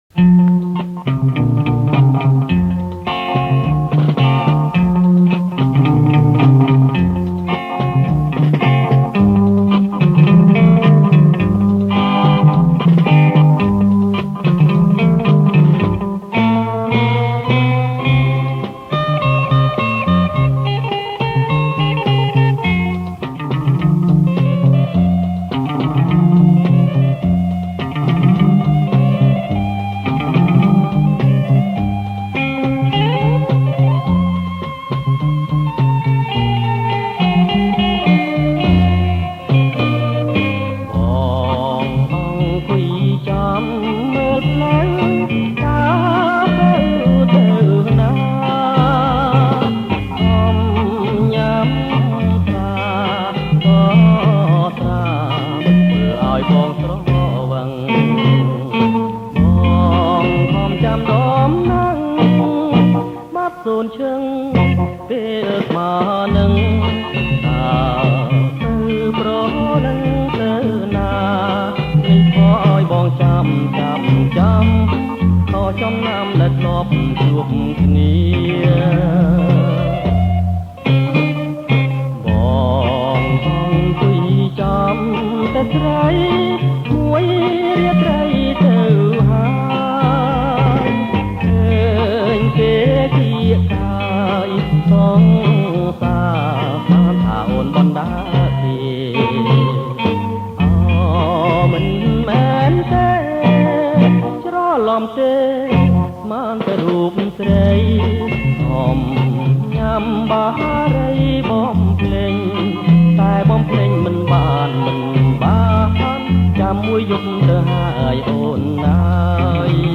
• ប្រគំជាចង្វាក់ Bolero Lent